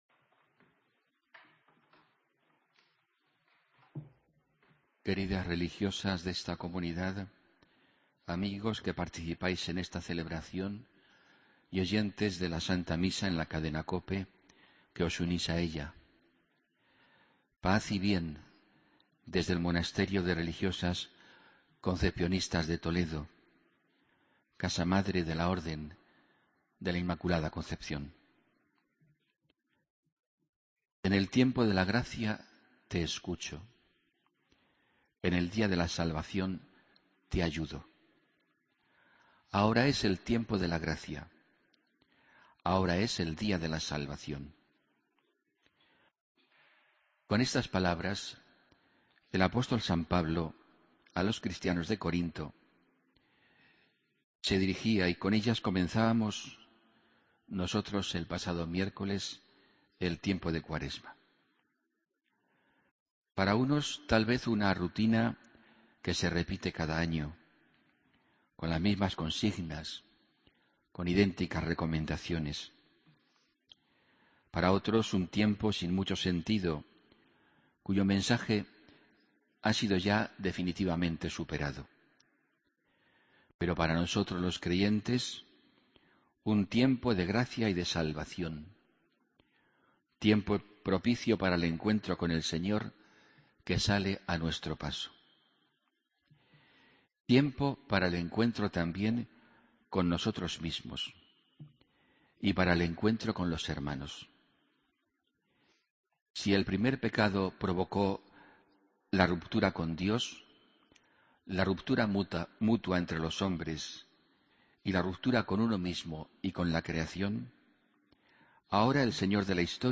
Homilía del domingo 22 de febrero de 2015